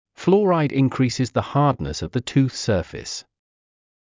ﾌﾛｰﾗｲﾄﾞ ｲﾝｸﾘｰｼｰｽﾞ ｻﾞ ﾊｰﾄﾞﾈｽ ｵﾌﾞ ｻﾞ ﾄｩｰｽ ｻｰﾌｪｲｽ